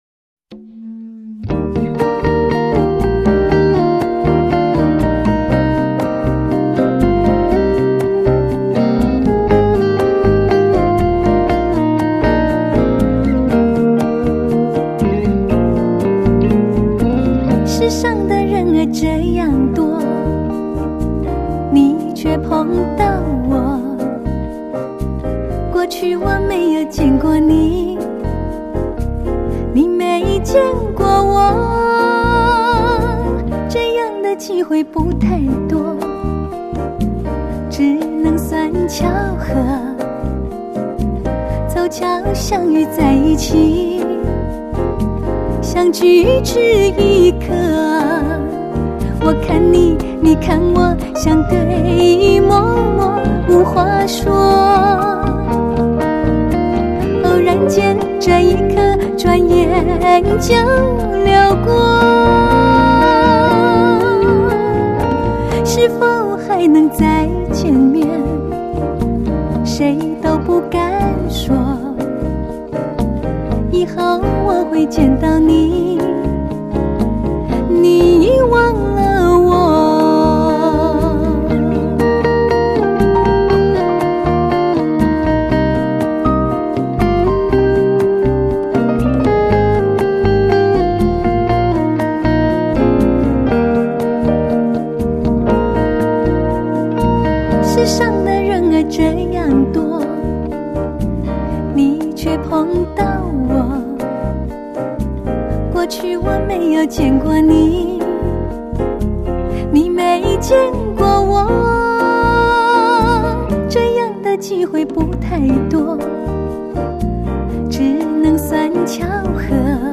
这12首情歌，都会让许多成年人勾起旧日的回忆，